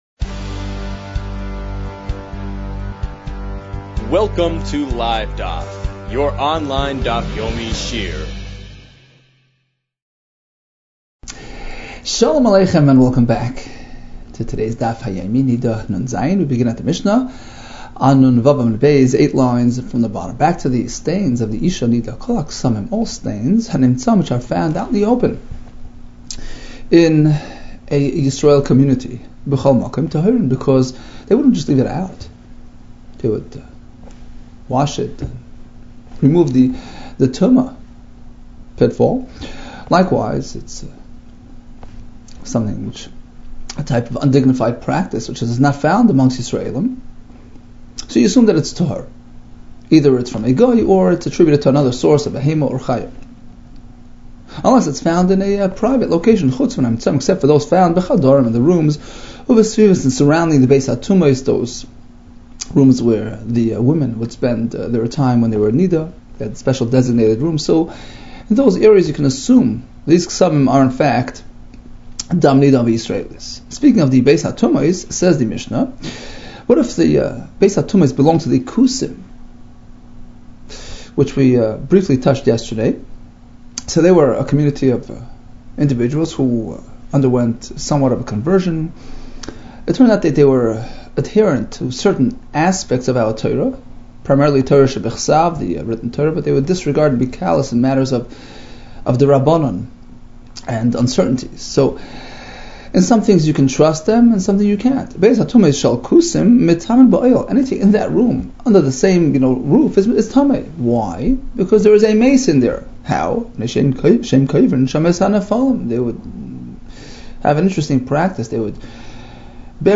Niddah 56 - נדה נו | Daf Yomi Online Shiur | Livedaf